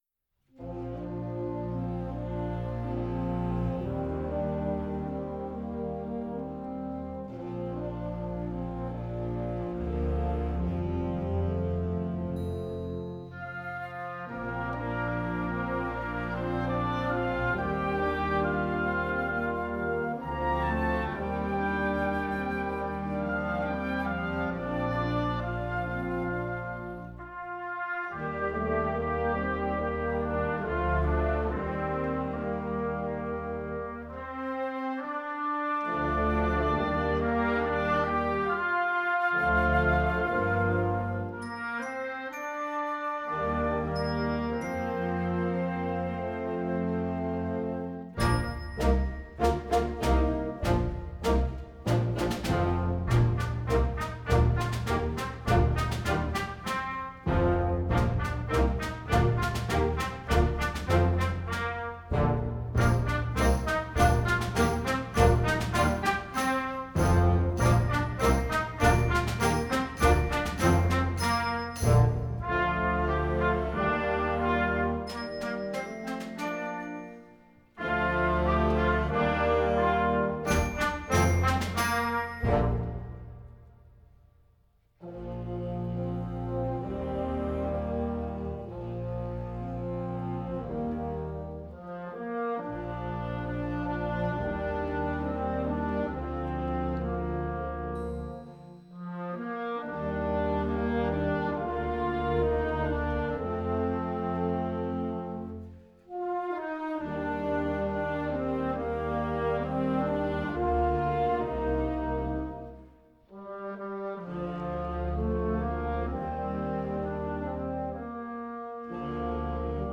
Gattung: Potpourri für Jugendblasorchester
Besetzung: Blasorchester